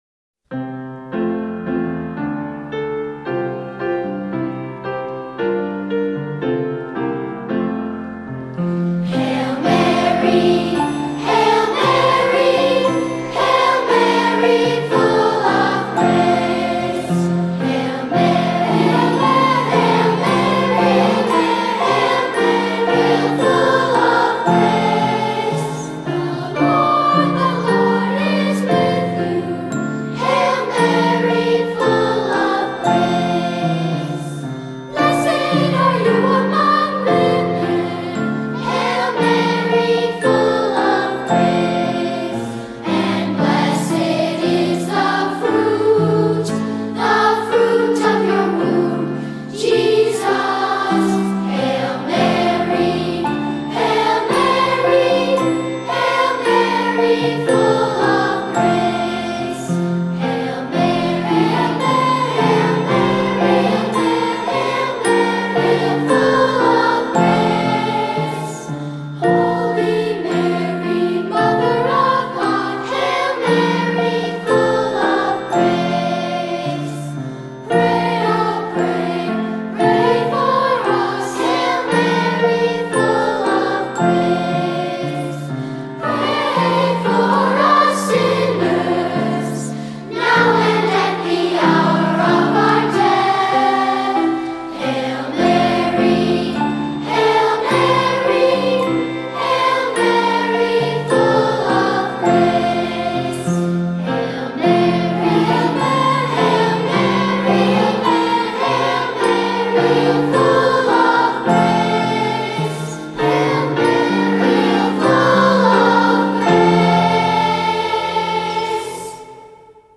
Voicing: Unison or Two-Part Choir, Assembly